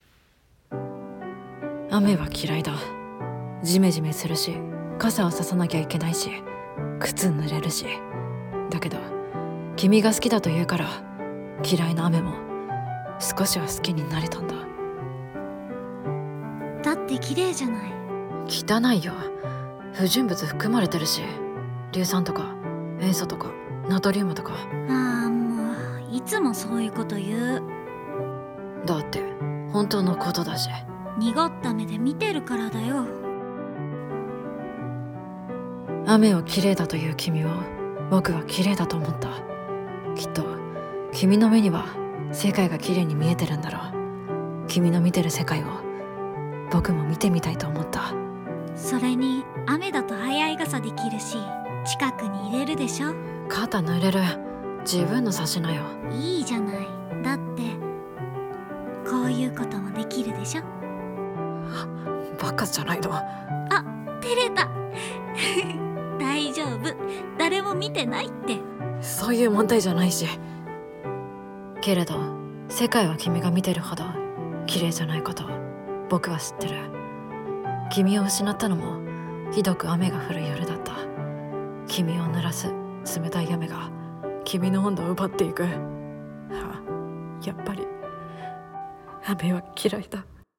【声劇】僕が嫌いな雨を好きだと言った君